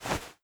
Player_Crouch 01.wav